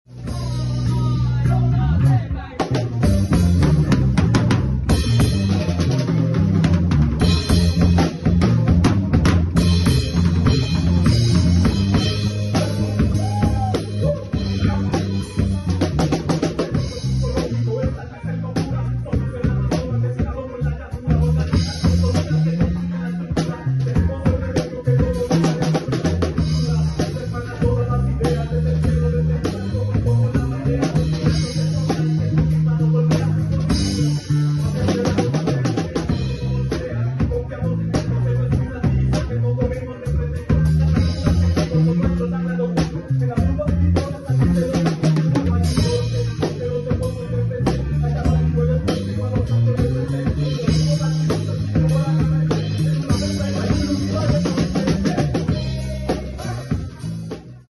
Drums Cam.